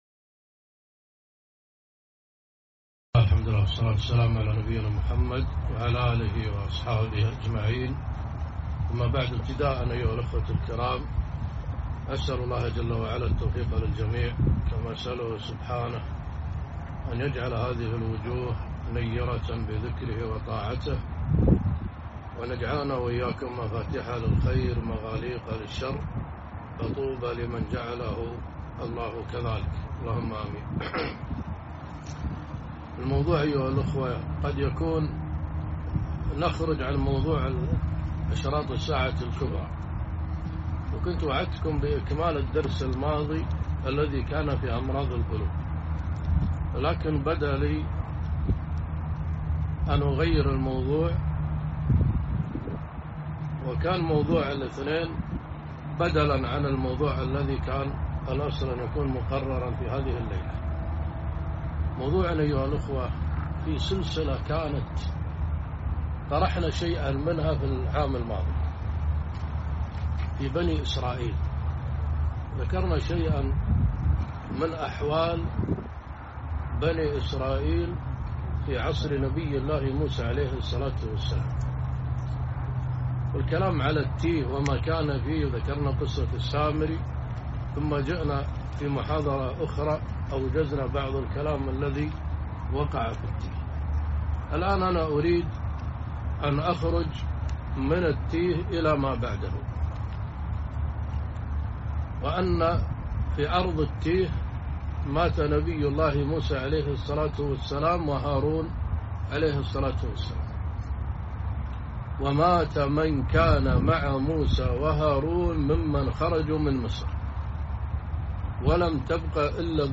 محاضرة - قصة طالوت وجالوت